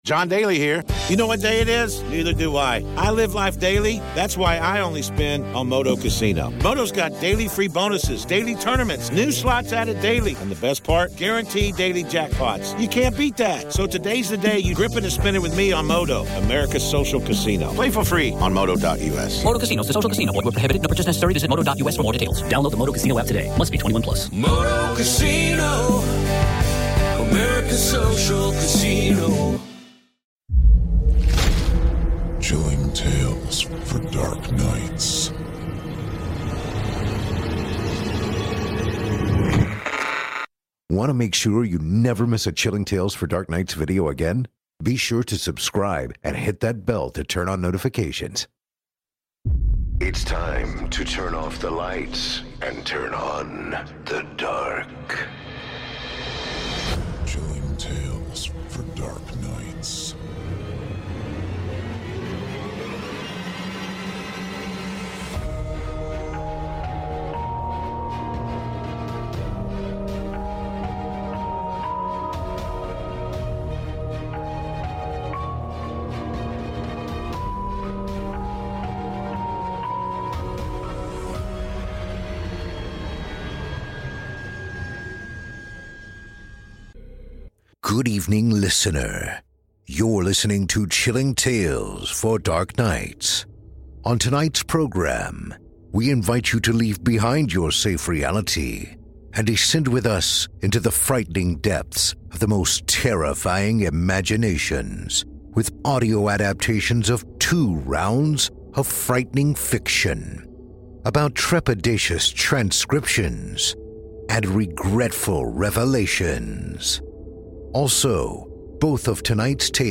This week's episode features audio adaptations of two rounds of frightening fiction about trepidatious transcriptions and regretful revelations.